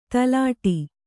♪ talāṭi